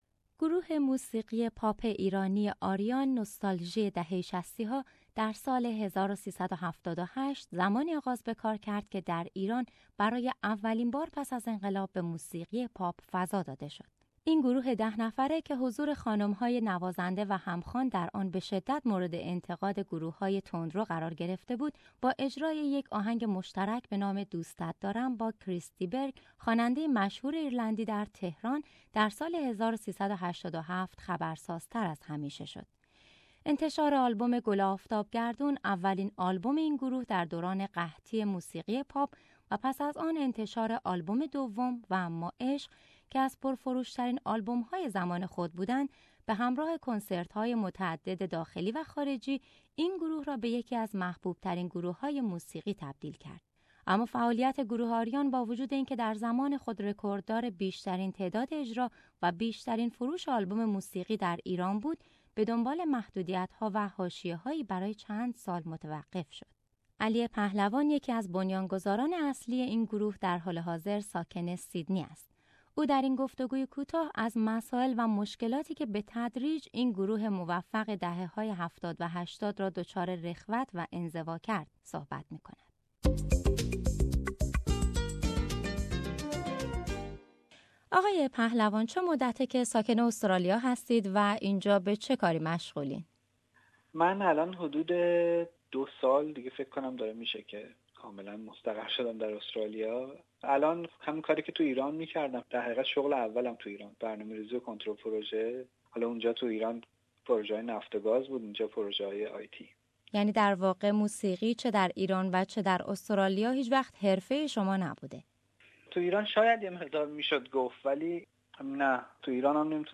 او در این گفتگوی کوتاه از مسائل و مشکلاتی که به تدریج این گروه موفق دهه های هفتاد و هشتاد را دچار رخوت و نزوا کرد، صحبت می کند.